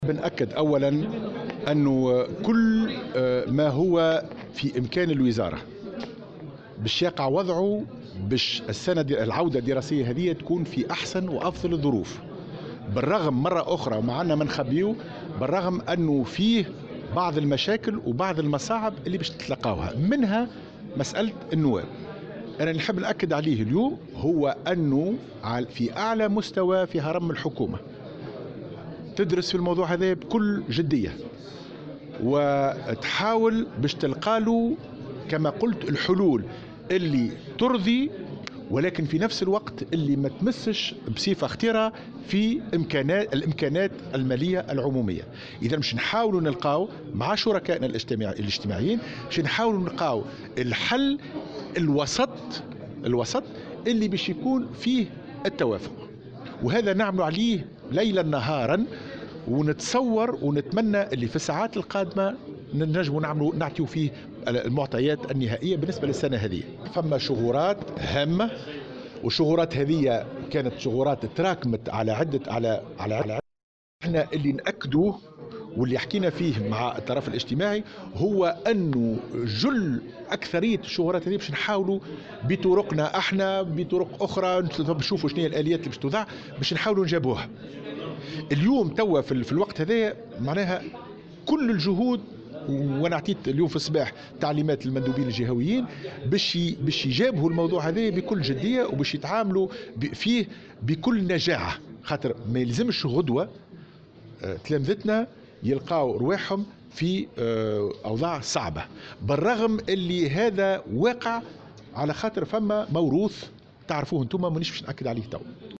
وأضاف في تصريح لمراسلة "الجوهرة أف أم" على هامش ندوة صحفية عقدتها الوزارة حول الاستعدادات للعودة المدرسية أنه سيتم التعامل مع هذا الملف بكل جدية و بالنجاعة المطلوبة.